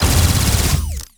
Added more sound effects.
GUNAuto_Plasmid Machinegun C Burst_06_SFRMS_SCIWPNS.wav